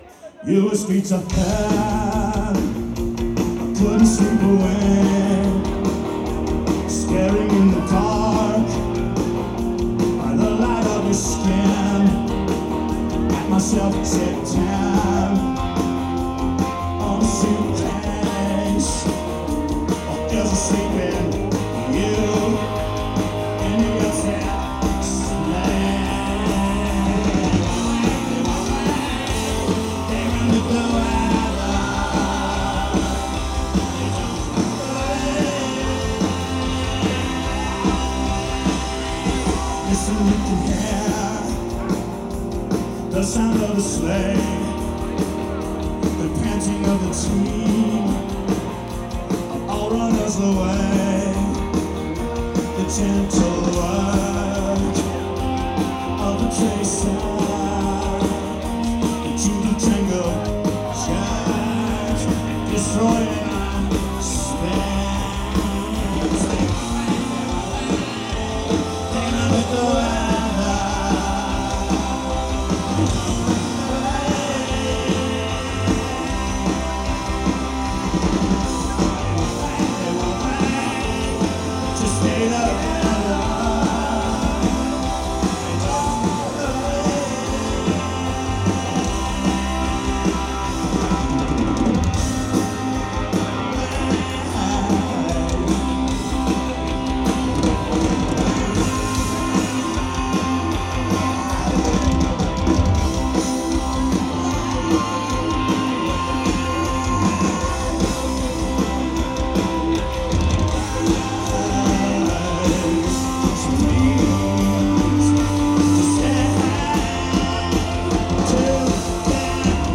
(8th Live Performance)